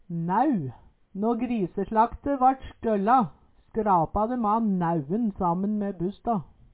næu - Numedalsmål (en-US)